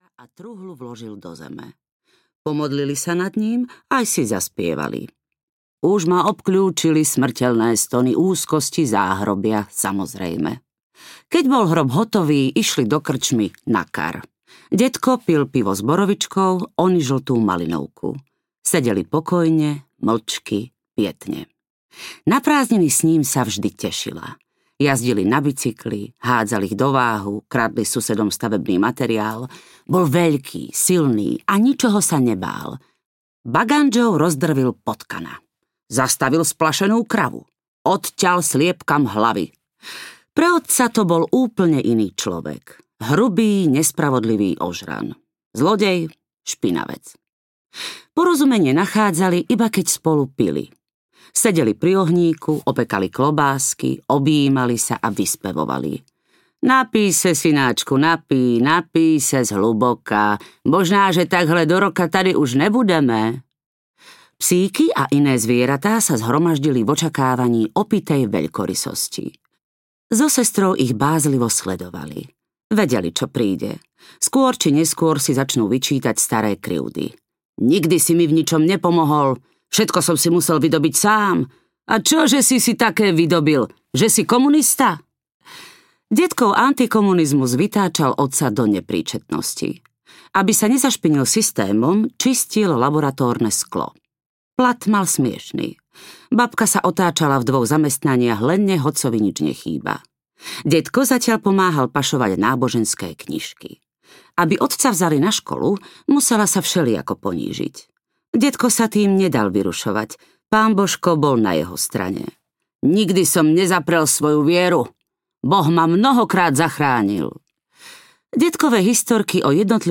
Úspešníci audiokniha
Ukázka z knihy